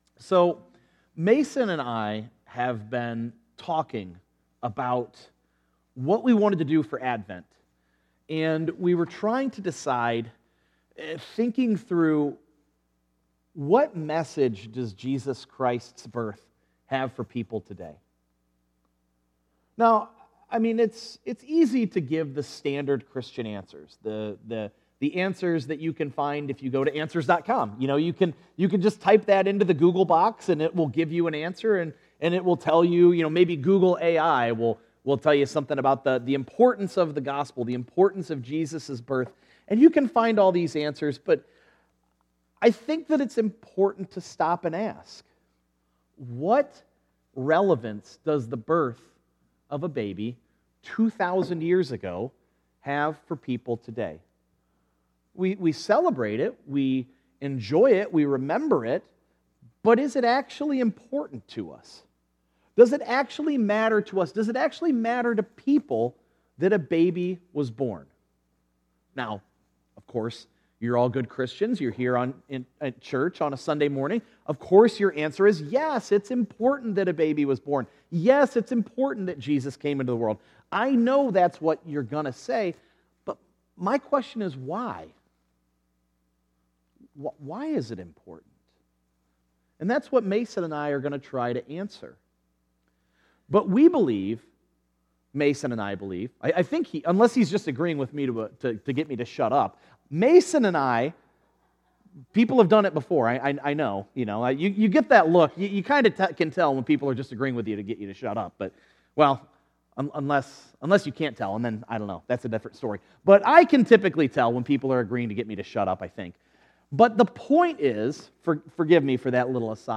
Sermons Archive
12_1_24_sunday_sermon.mp3